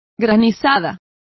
Complete with pronunciation of the translation of hailstorm.